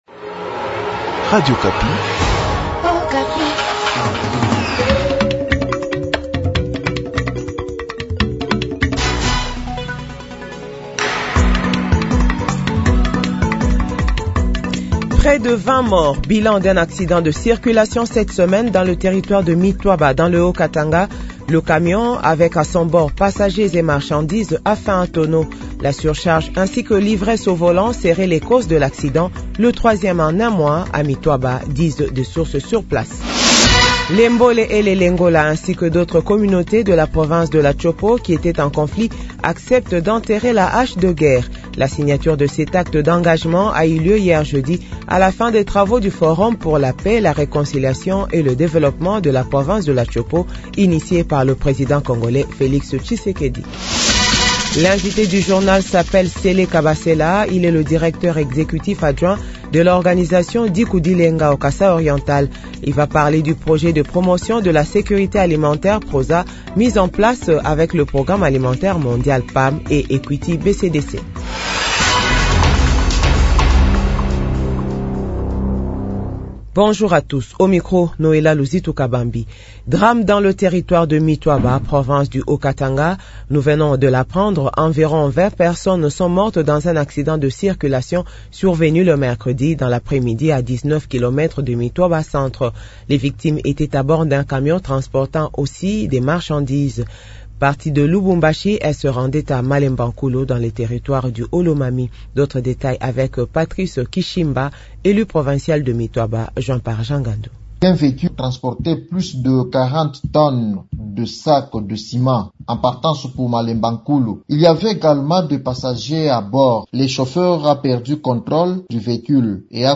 JOURNAL FRANÇAIS DE 12H00